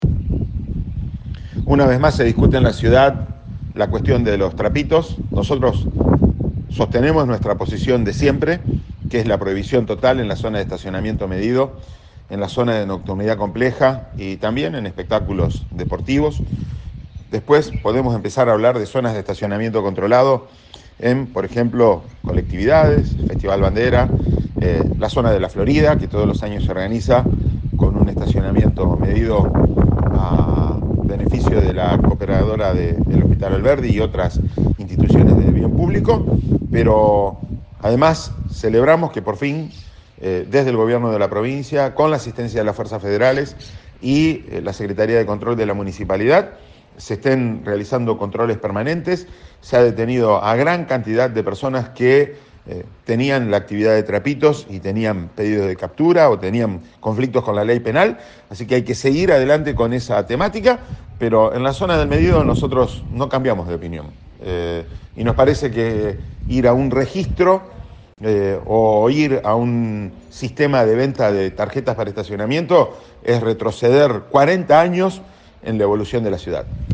Diego Herrera, secretario de Control de la Municipalidad de Rosario, habló con el móvil de Cadena 3 Rosario, en Siempre Juntos, y explicó que "nosotros arrancamos en abril, junto con la policía de la provincia de Santa Fe".